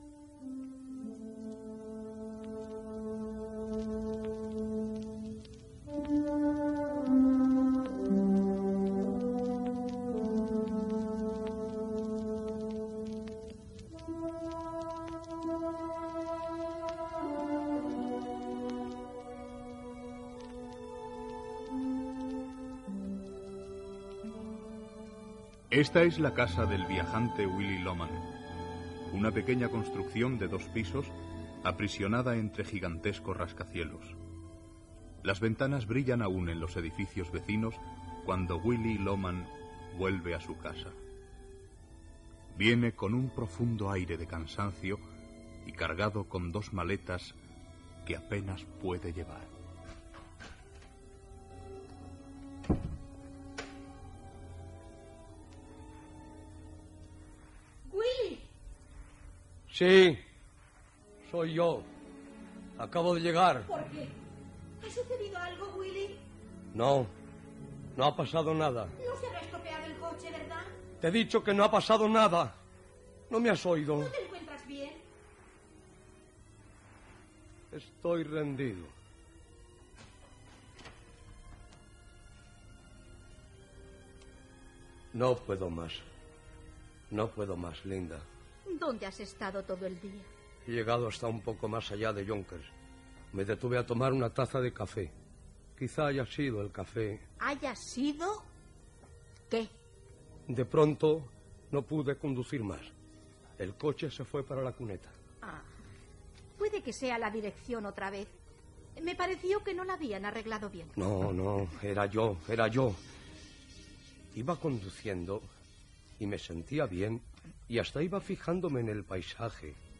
0635ddf35dcb9548a276187a7c2bbe5485f95101.mp3 Títol Ràdio Barcelona Emissora Ràdio Barcelona Cadena SER Titularitat Privada estatal Nom programa Gran Radioteatro de Radio Barcelona Descripció Minuts inicials de l'adaptació radiofònica de "La muerte de un viajante" d'Arthur Miller. Gènere radiofònic Ficció